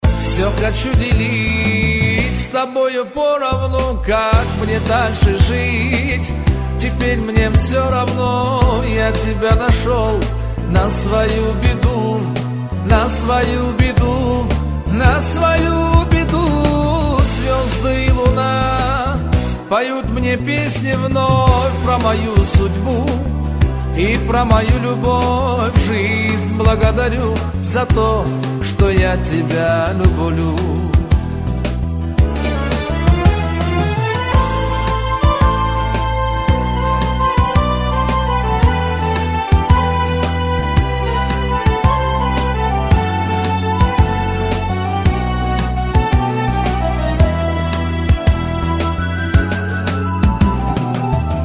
Рингтоны » Восточные